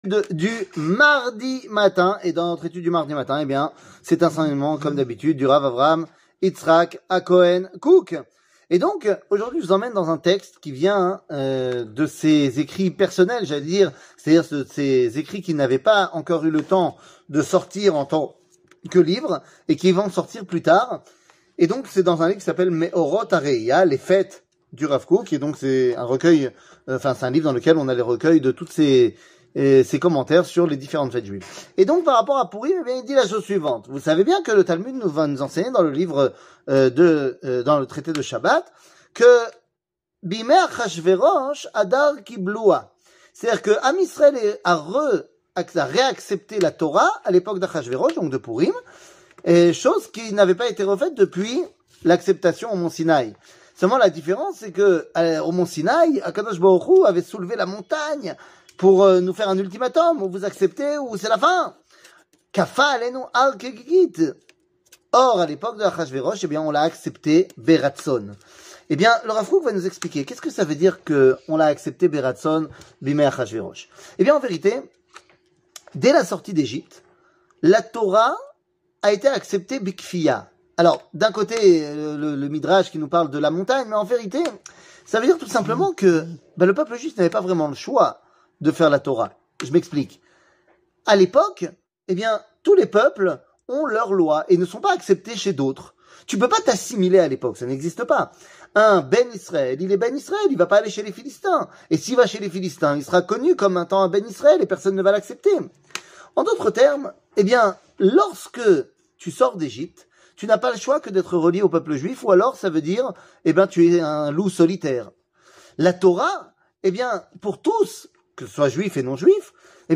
Rav Kook, Le choix de Pourim, Meorot Arehiya 00:04:03 Rav Kook, Le choix de Pourim, Meorot Arehiya שיעור מ 28 פברואר 2023 04MIN הורדה בקובץ אודיו MP3 (3.7 Mo) הורדה בקובץ וידאו MP4 (16.1 Mo) TAGS : שיעורים קצרים